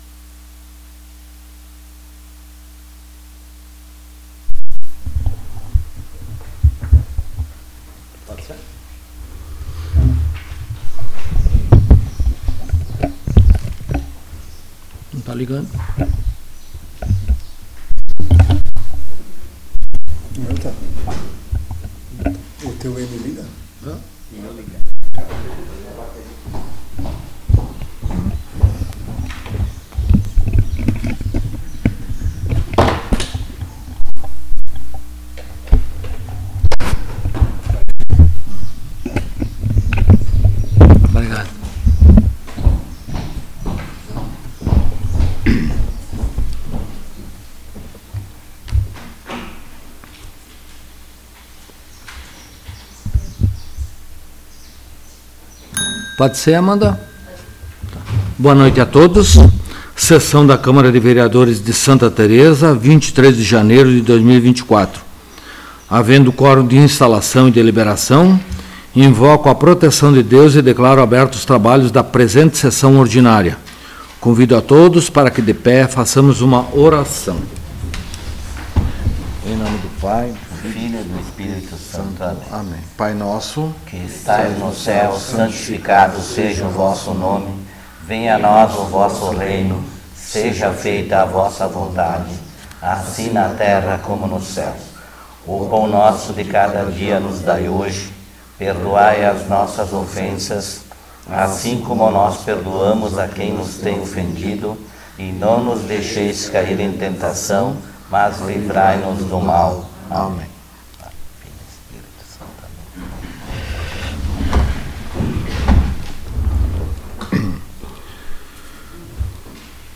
2ª Sessão Ordinária de 2024
Local: Câmara Municipal de Vereadores de Santa Tereza